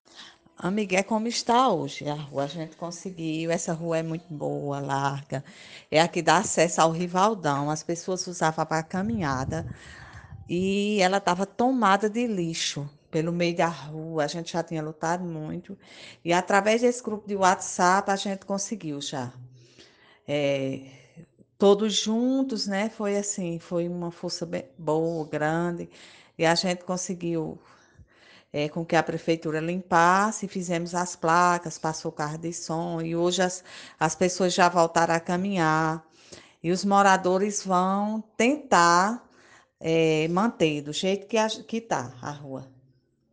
Ouça o depoimento de uma moradora:
moradora.mp3